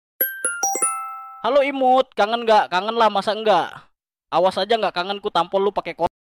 Notif Pesan Masuk Untuk Hp Sound Effects Free Download